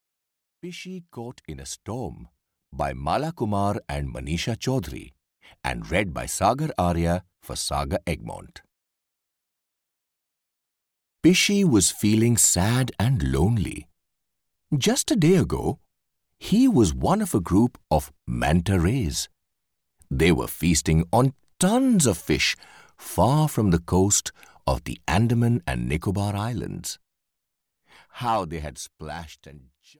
Pishi Caught in a Storm (EN) audiokniha
Ukázka z knihy